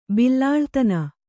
♪ billa